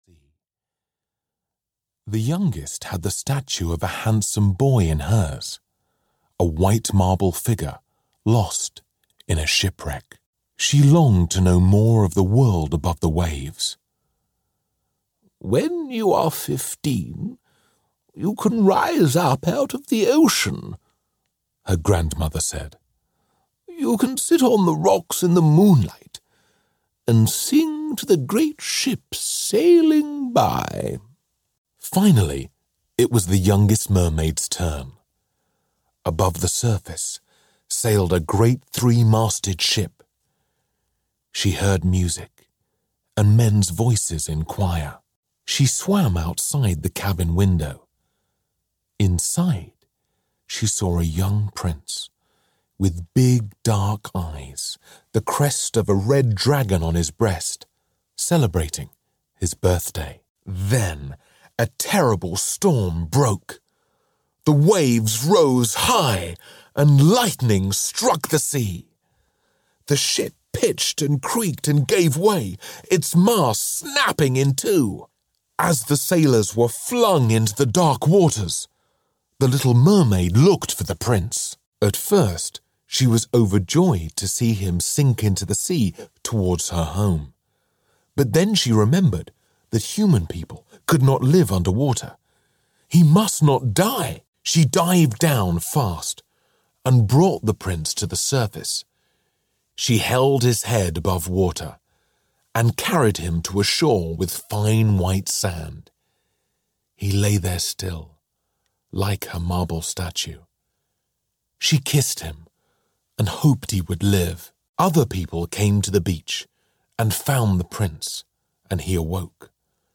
The most beloved fairy tales of Danish author, Hans Christian Andersen, narrated by the very best of British stage and screen talent.
Ukázka z knihy
• InterpretMichael Caine, Joan Collins, Roger Moore, Stephen Fry, Paul Mckenna, Michael Ball, Joanna Lumley, Ewan Mcgregor, Emma Samms, David Walliams, Charlotte Rampling